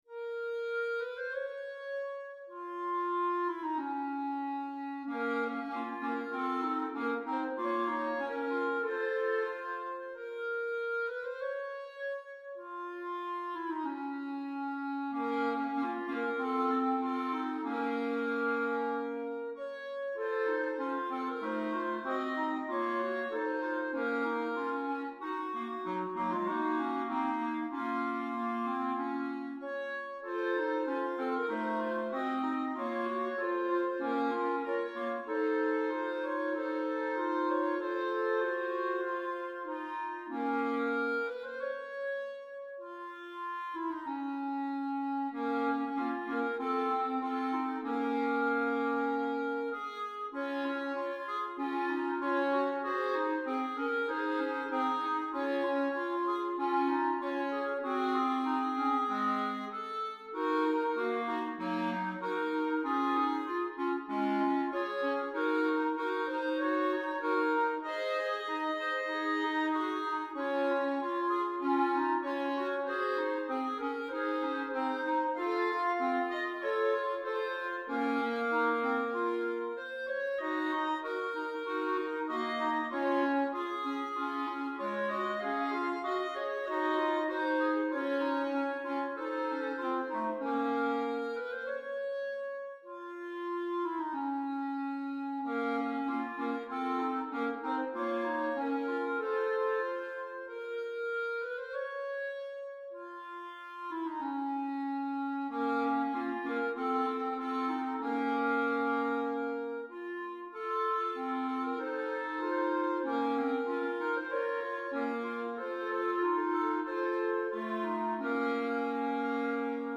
set for 3 like-instruments